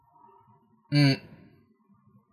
n